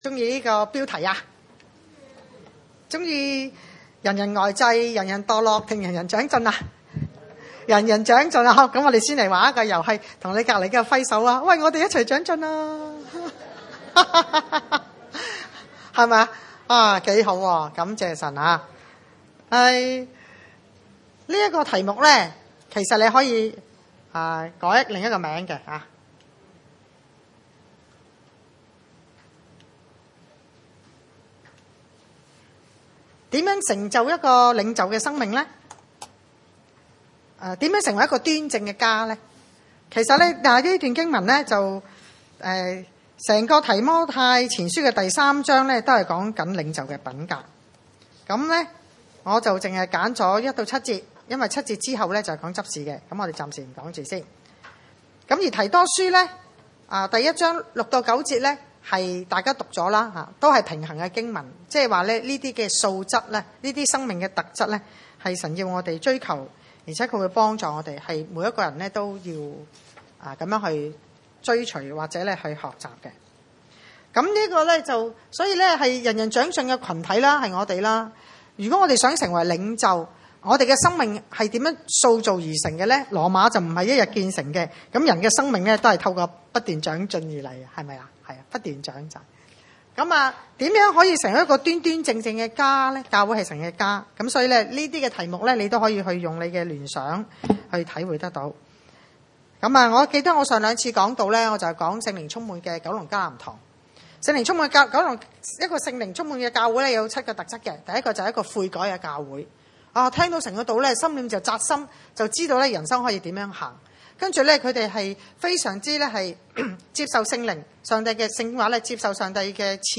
提前 三：1-7、 提多書一:6-9 崇拜類別: 主日午堂崇拜 1.人若想要得監督的職分、就是羨慕善工．這話是可信的． 2.作監督的、必須無可指責、只作一個婦人的丈夫、有節制、自守、端正、樂意接待遠人、善於教導． 3.不因酒滋事、不打人、只要溫和、不爭競、不貪 4.好好管理自己的家、使兒女凡事端莊順服。